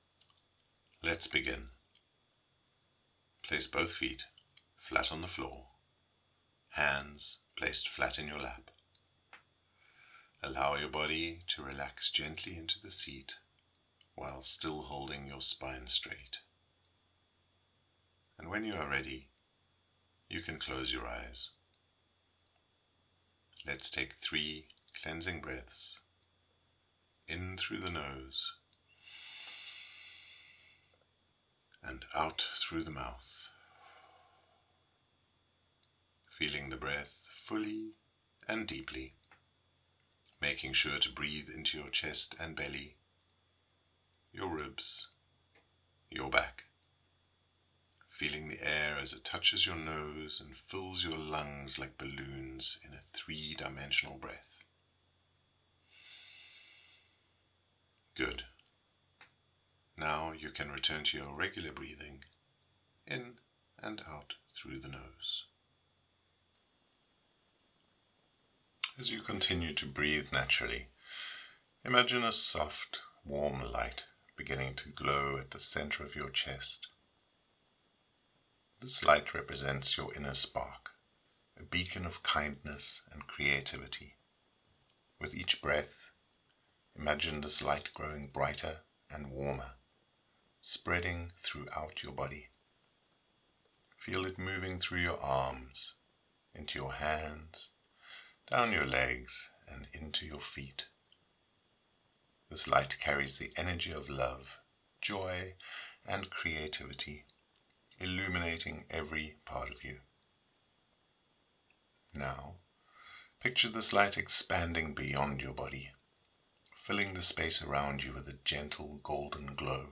Sparkly Season Meditation
YE10-meditation.mp3